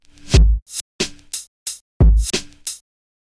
逆转的嘻哈鼓 90 BPM
Tag: 90 bpm Hip Hop Loops Drum Loops 574.39 KB wav Key : Unknown